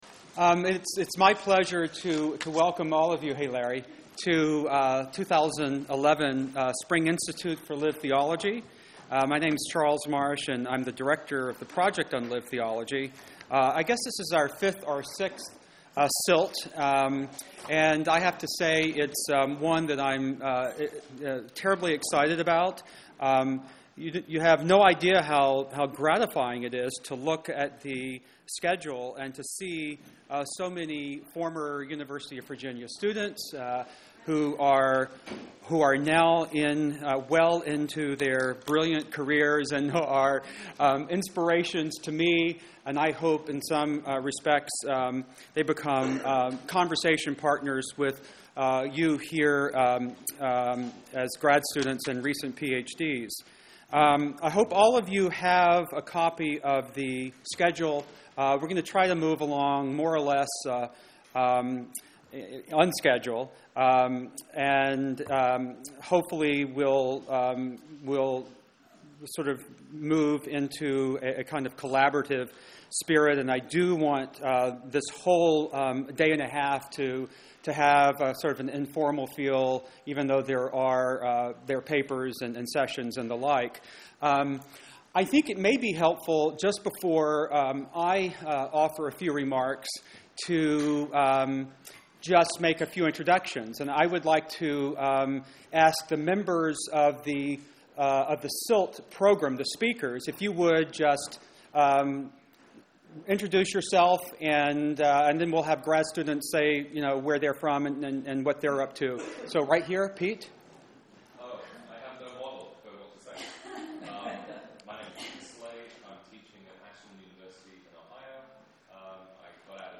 The paper itself begins at the 12:45 minute mark.
Audio Information Date Recorded: May 25, 2011 Location Recorded: Charlottesville, VA Audio File: Download File » This audio is published by the Project on Lived Theology (PLT).